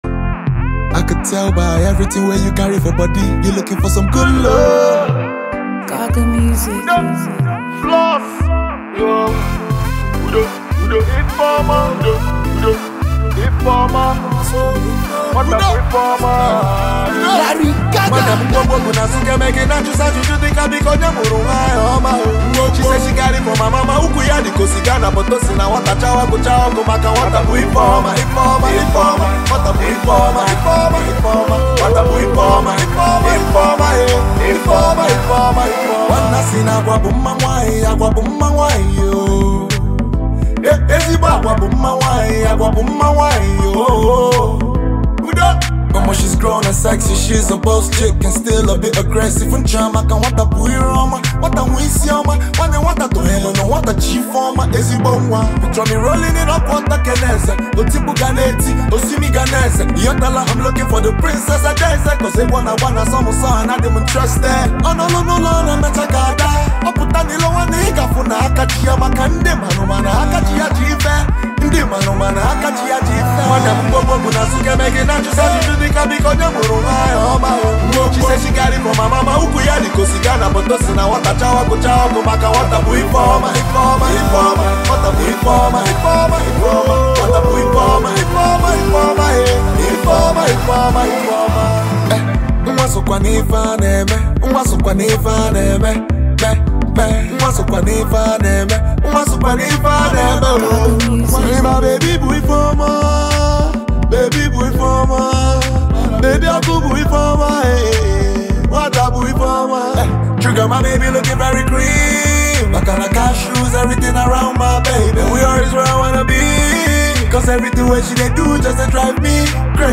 Gifted Nigerian singer